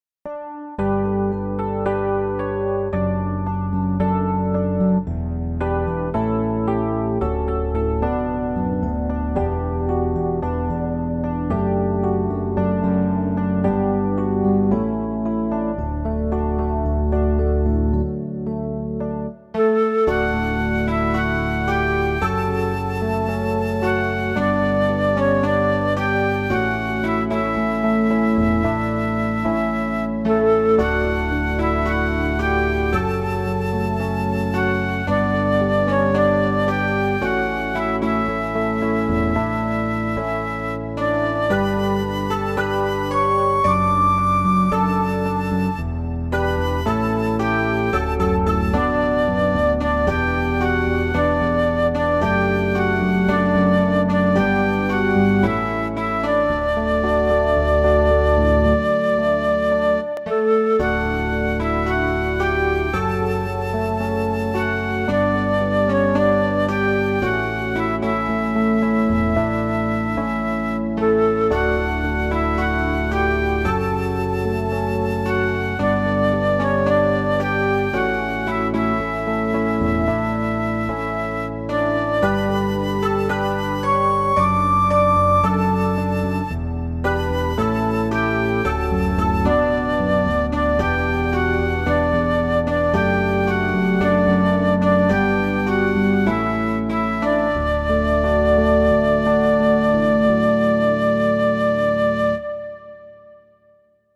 ...... you can find earlier messages in this series on the Sermon Page Closing Hymn : .